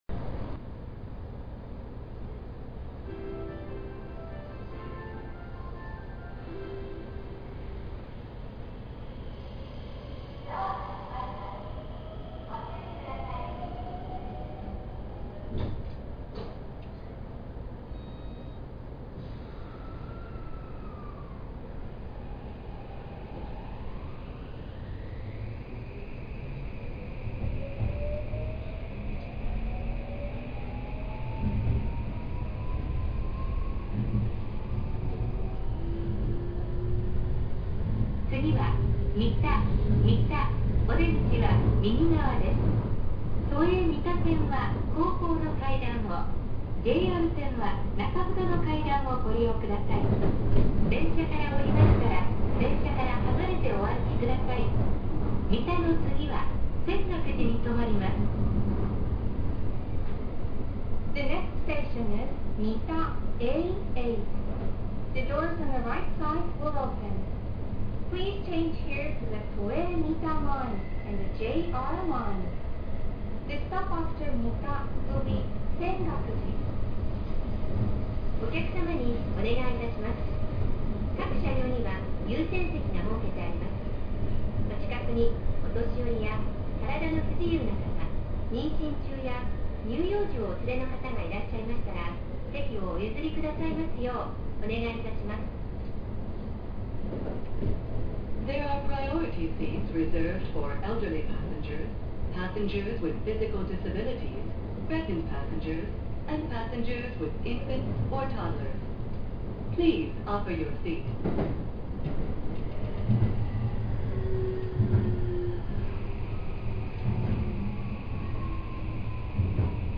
・5500形走行音
自動放送が搭載されているのは5300形と同様ですが、音質がとてもクリアなものとなった為聞こえやすくなりました。走行装置は三菱SiCのVVVF。なかなか特徴的な音で、近年の新型車としてはかなり個性が出ている部類なのではないでしょうか。